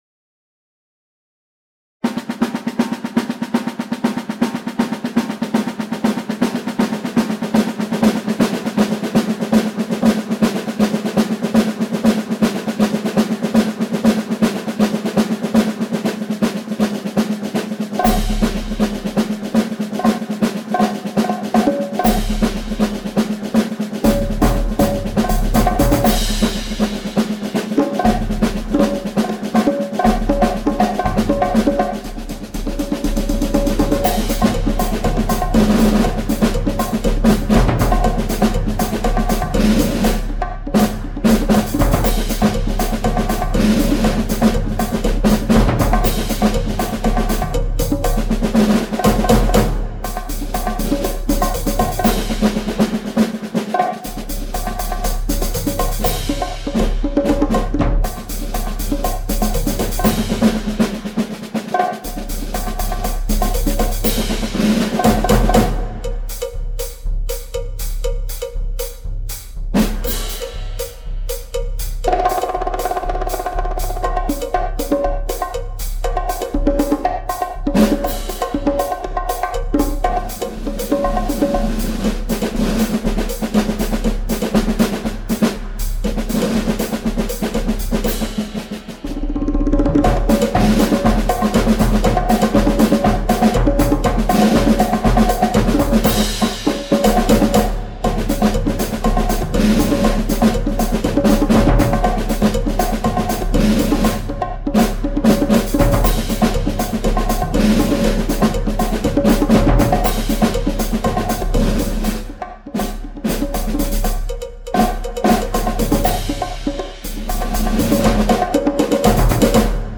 stimmlose Percussion.